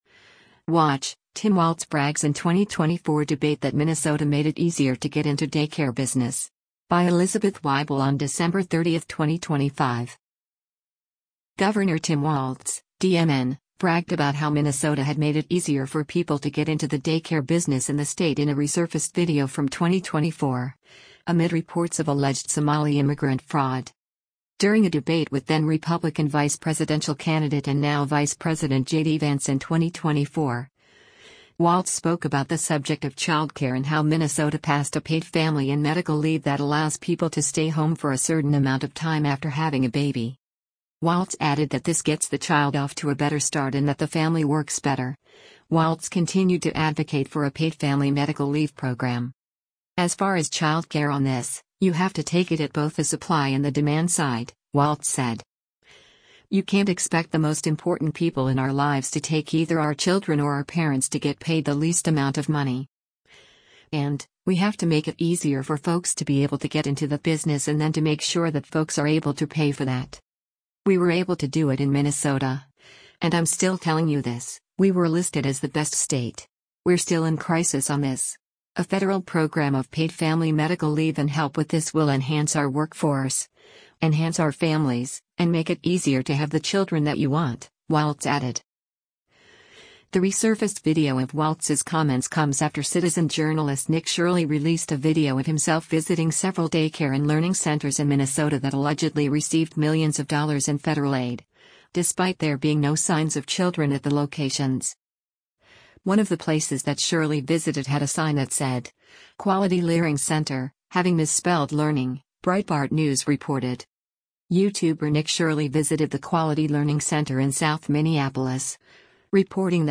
WATCH: Tim Walz Brags in 2024 Debate that Minnesota Made ‘It Easier’ to Get into Daycare Business
During a debate with then-Republican vice presidential candidate and now-Vice President JD Vance in 2024, Walz spoke about the subject of childcare and how Minnesota “passed a paid family and medical leave” that allows people to stay home for a certain amount of time after having a baby.